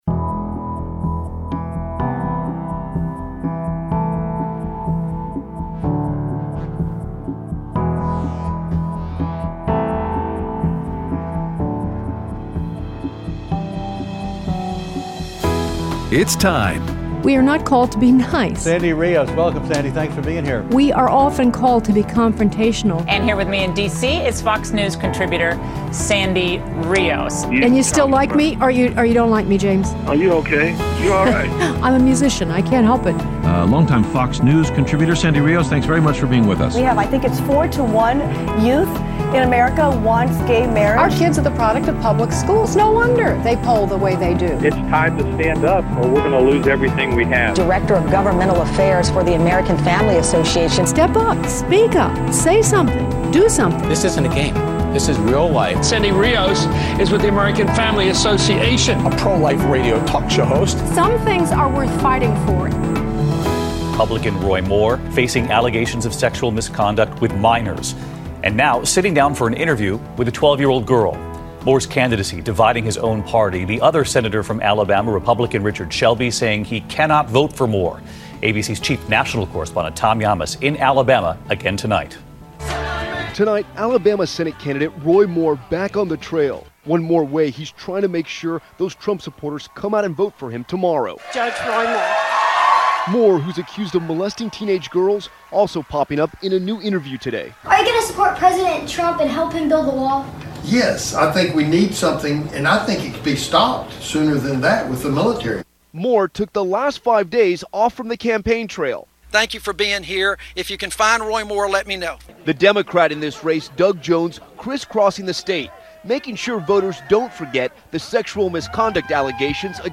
Aired Tuesday 12/12/17 on AFR 7:05AM - 8:00AM CST